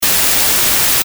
pic 3-8: typical sawtooth, square and noise waveform with corresponding harmonics
noise.mp3